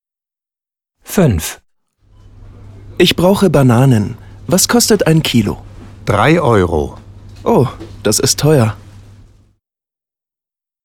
Dialog 5: